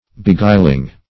Beguiling \Be*guil"ing\, a.